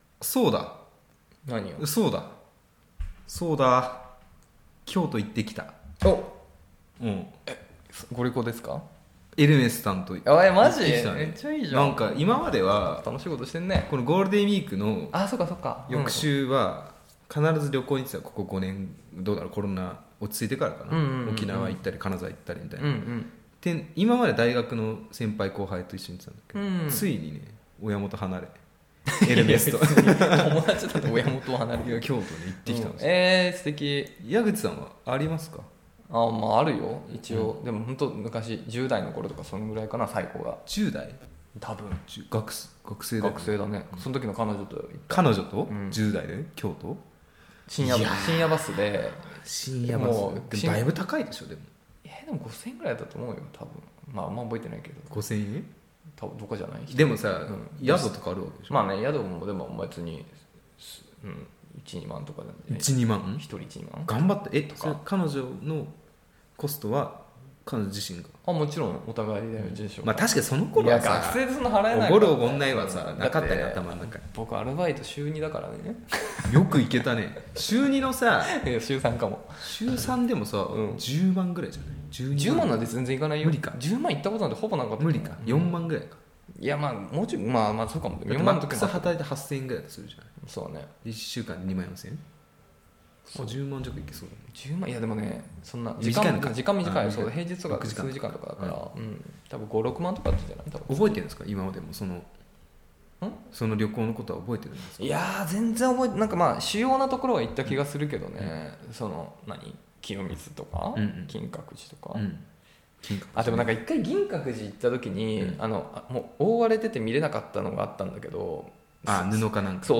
恋の街 中野よりアラサー男が恋愛トークをお届けします！